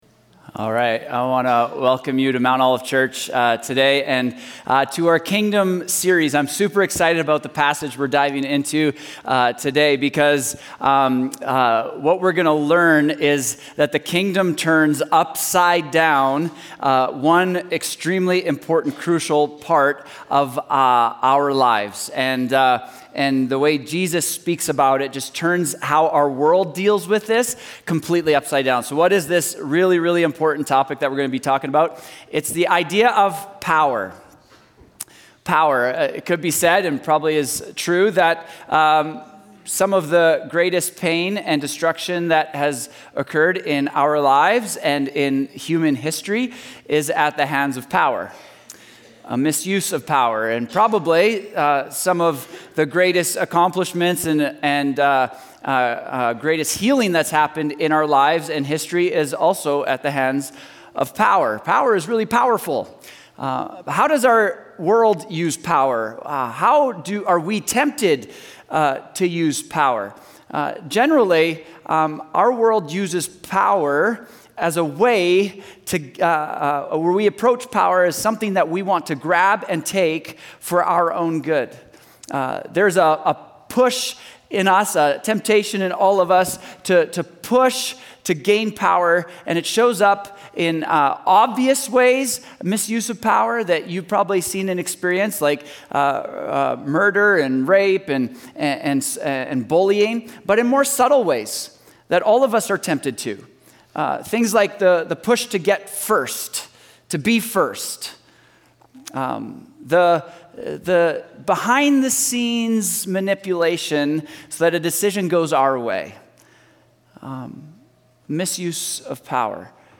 Sermons | Mount Olive Church